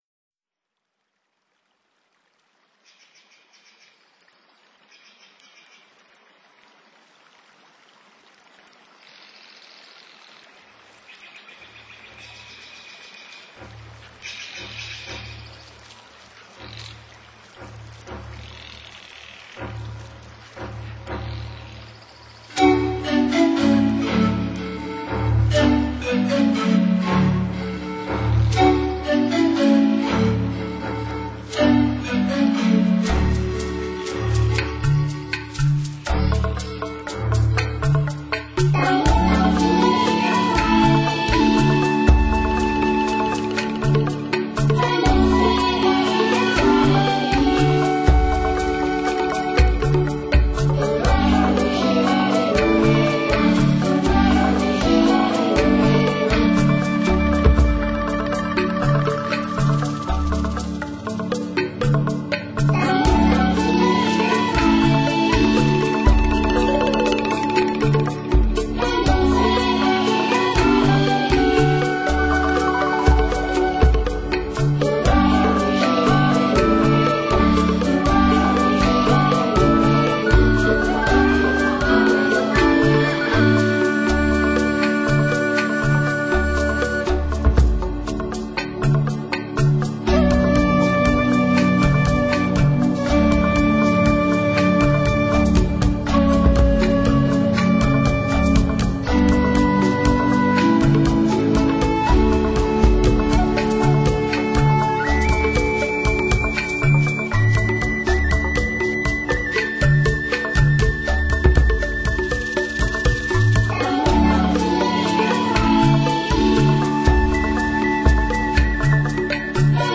强烈的节奏。